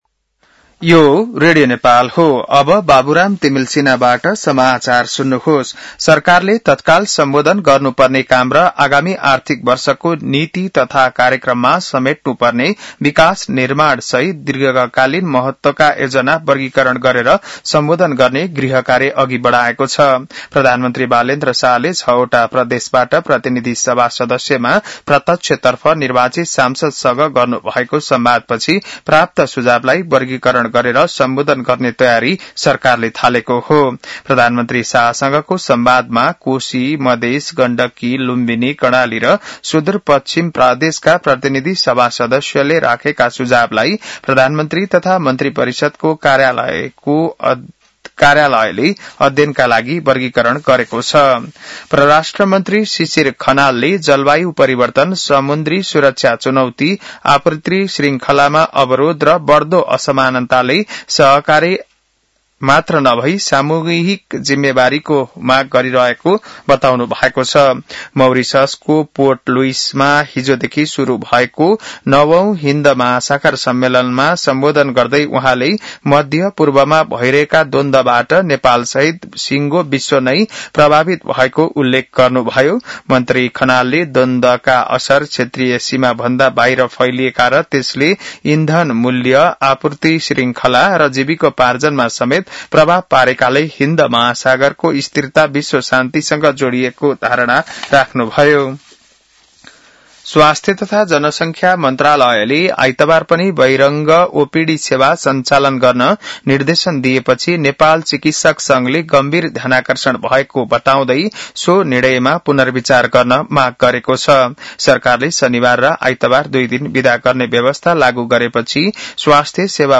बिहान १० बजेको नेपाली समाचार : २९ चैत , २०८२